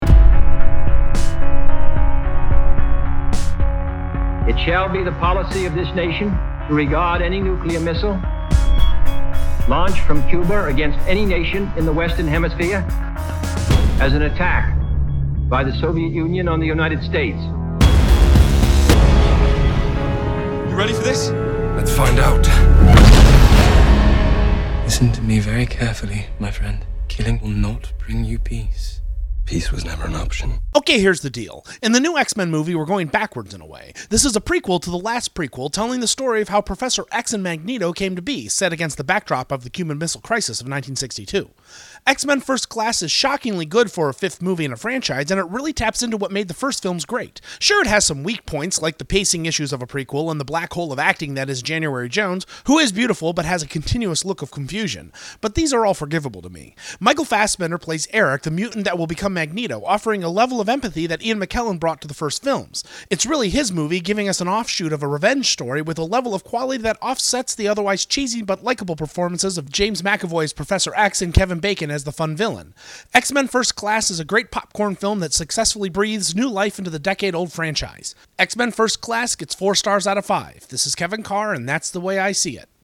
'X-Men: First Class' Movie Review